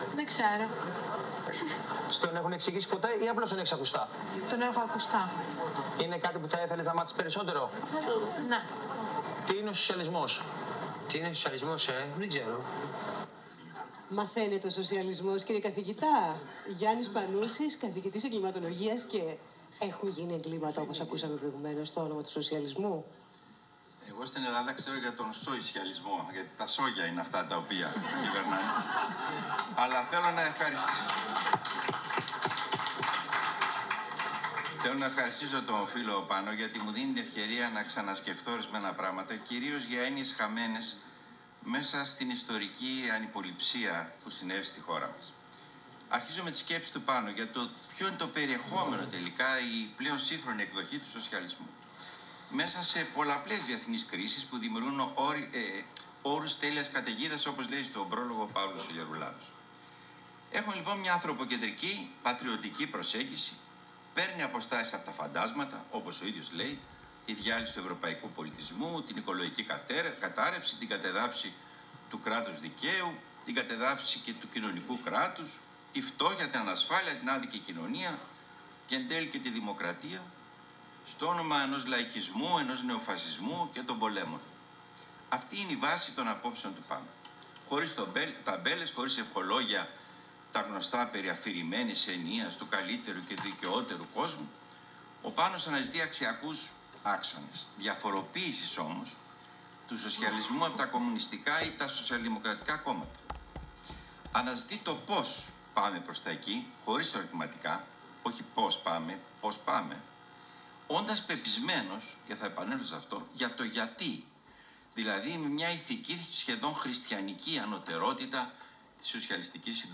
ΟΜΙΛΙΑ 14/1/26, στην παρουσίαση του βιβλίου “Σοσιαλισμός;” του Πάνου Μπιτσαξή, εκδόσεις Λιβάνη.